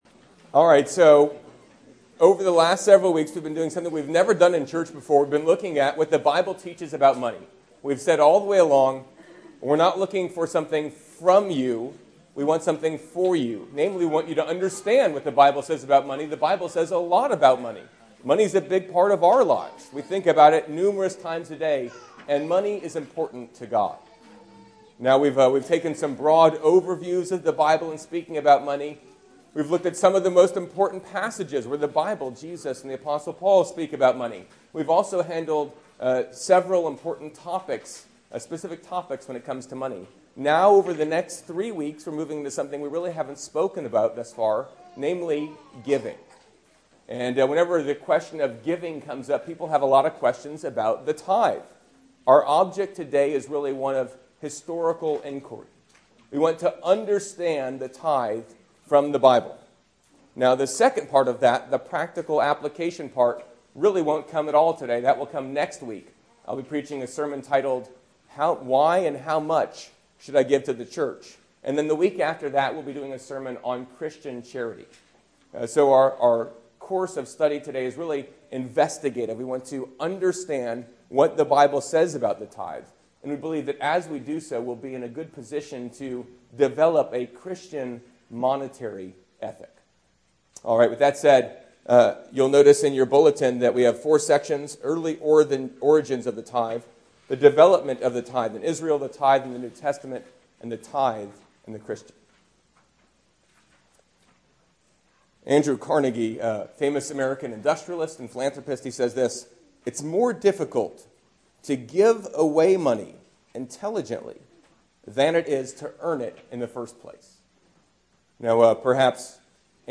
Sermons - God and Money < King of Kings, PCA